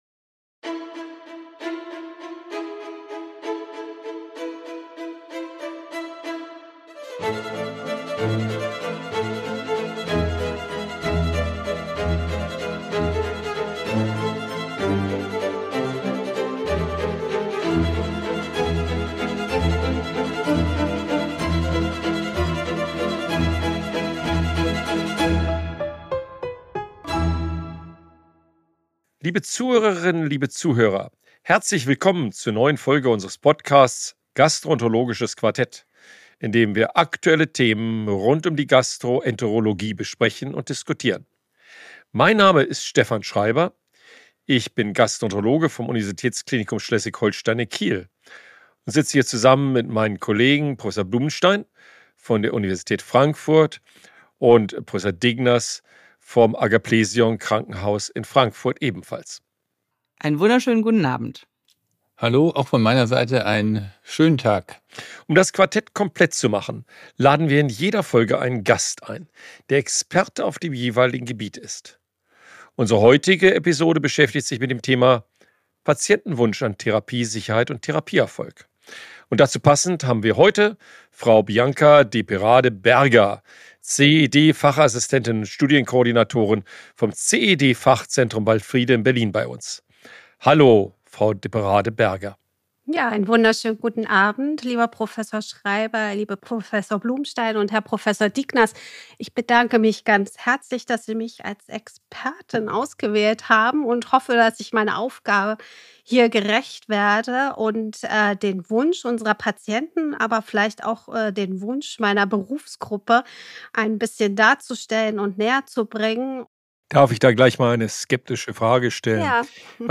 Die Idee: Eine CED-Fachassistenz als Bindeglied zwischen medizinischem Personal und Patientinnen und Patienten. Im Gespräch mit Das Gastroenterologische Quartett